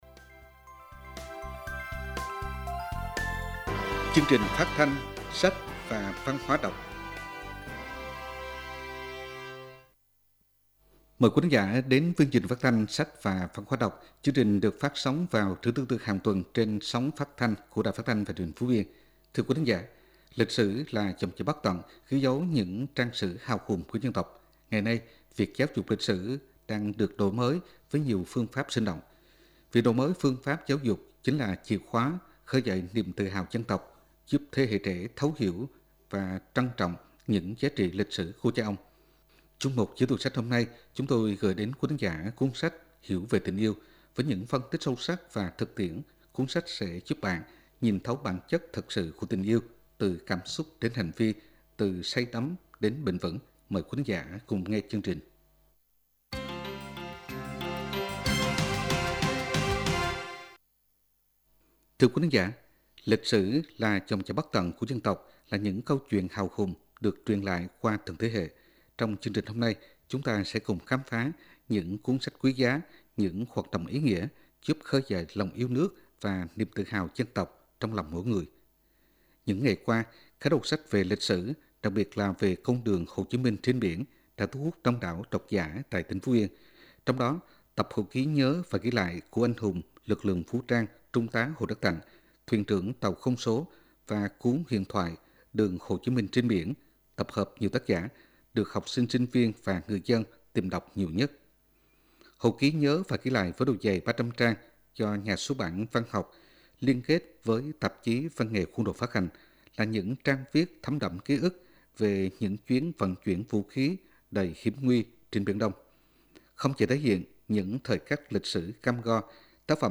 Chương trình được phát sóng vào trưa thứ Tư hàng tuần trên sóng của Đài Phát thanh và Truyền hình Phú Yên.